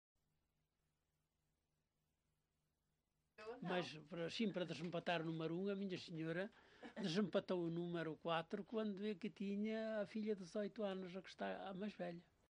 LocalidadeCastro Laboreiro (Melgaço, Viana do Castelo)